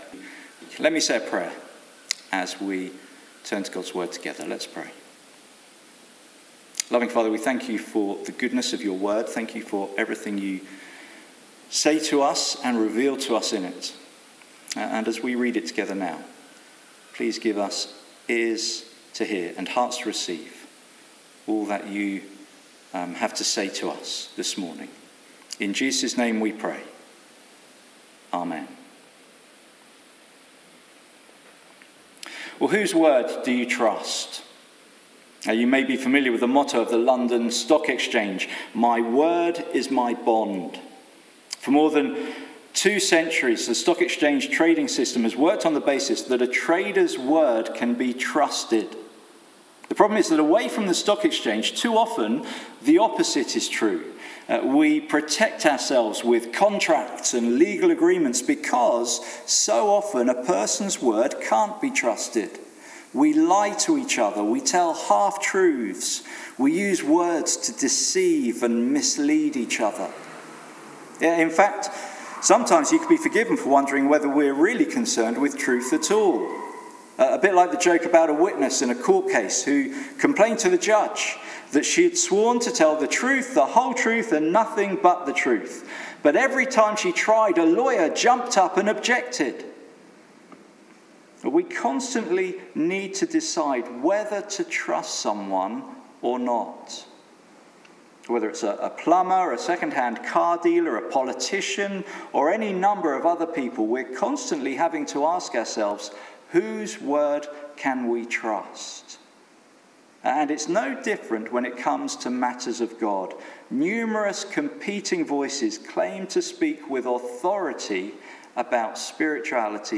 Exposition of 1 Kings Passage: 1 Kings 17:1-24 Service Type: Sunday Morning Sermon Transcript « Restored